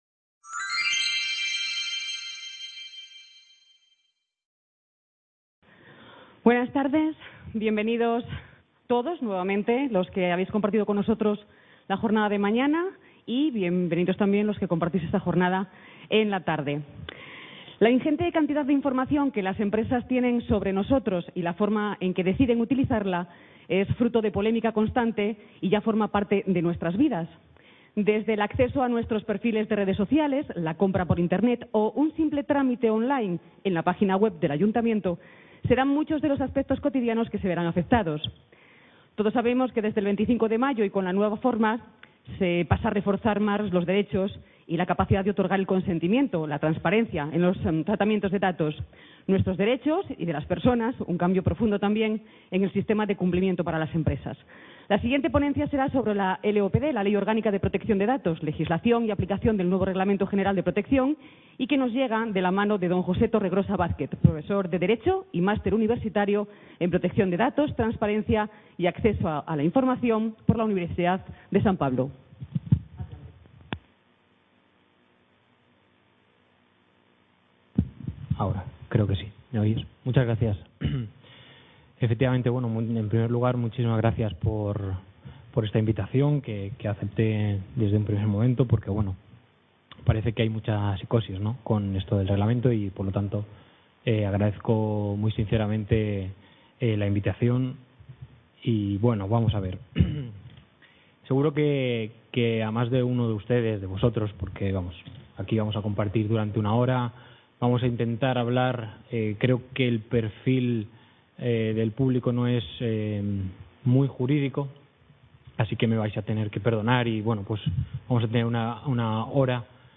CA Ponferrada - CYBERWORKING 2018, Ciberseguridad y Territorios Inteligentes para un Mundo Conectado